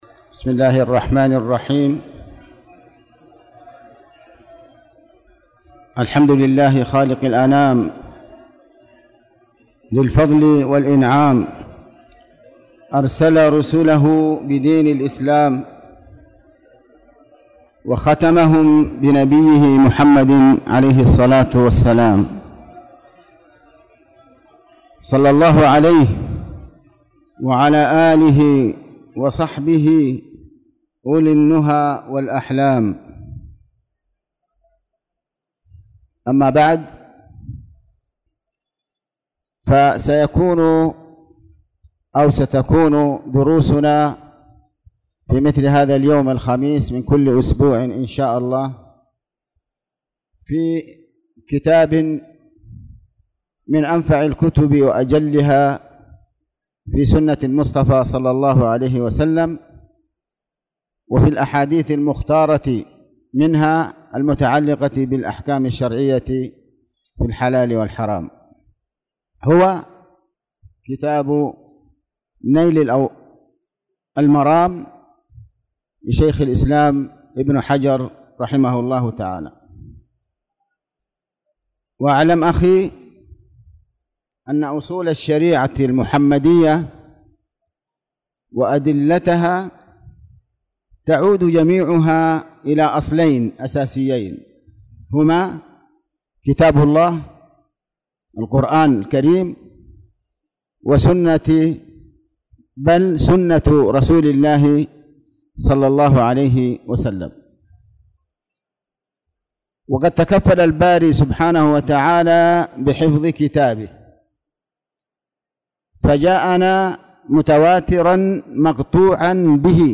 تاريخ النشر ١٦ شوال ١٤٣٧ هـ المكان: المسجد الحرام الشيخ